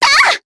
Rodina-Vox_Attack4_jp.wav